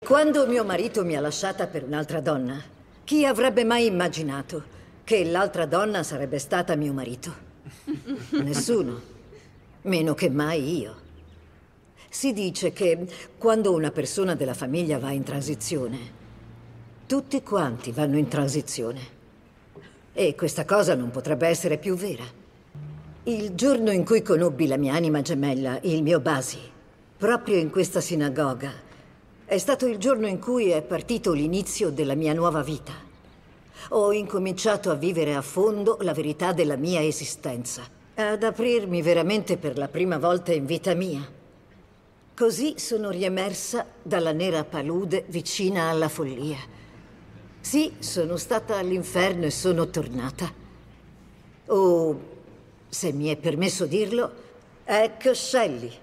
nel telefilm "Transparent", in cui doppia Judith Light.